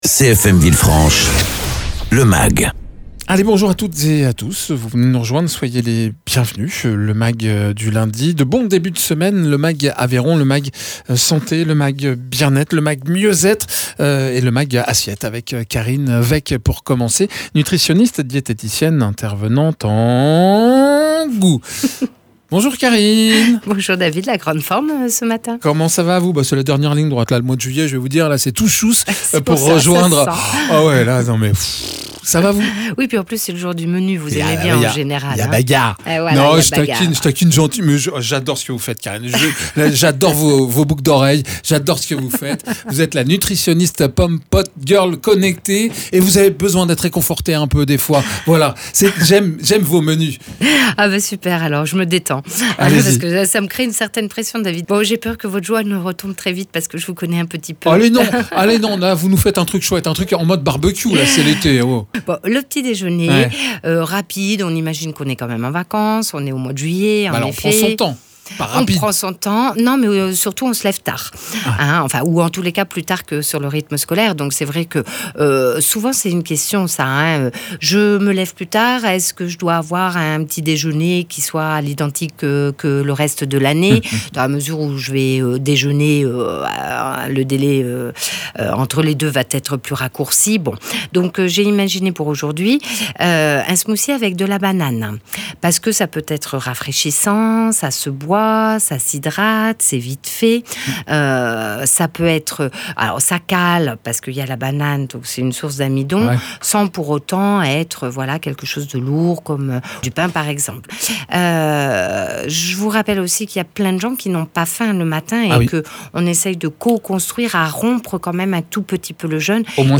nutritionniste diététicien
paysan spécialisé dans les plantes aromatiques et médicinales et en biodynamie